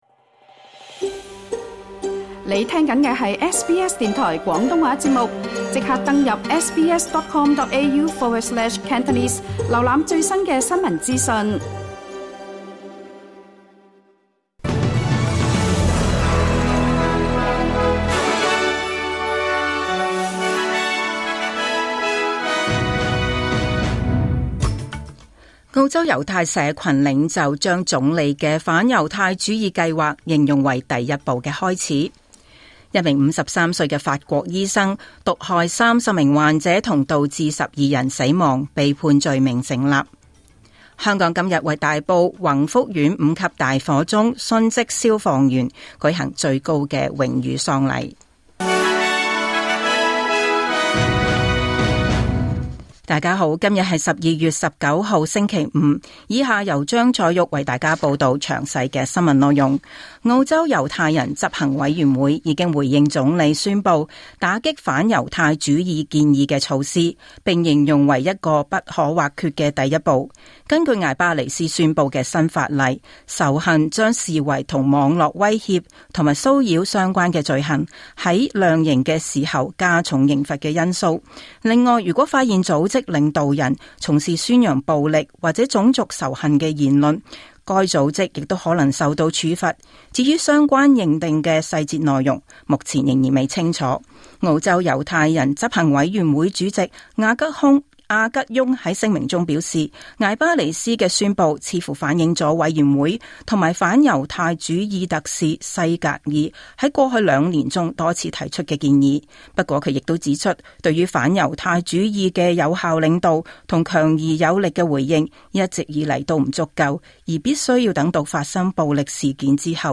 2025年12月19日SBS廣東話節目九點半新聞報道。